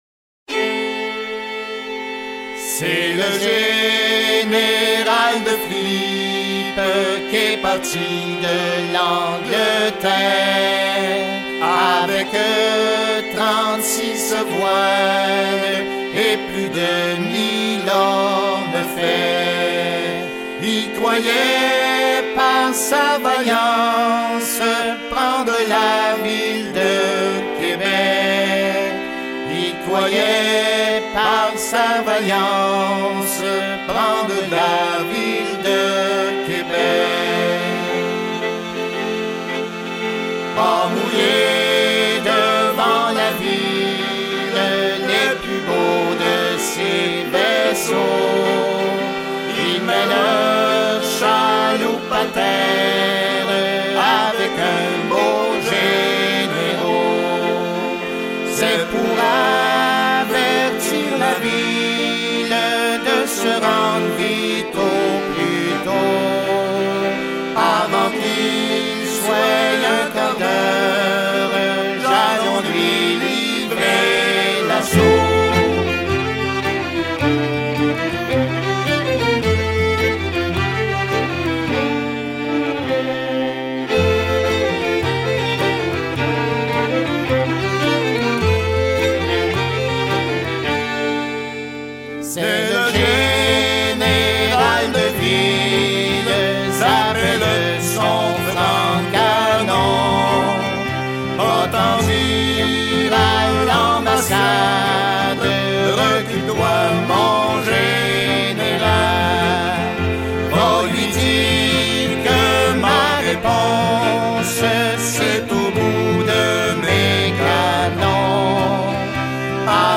enregistré en 1946 sur cylindre de cire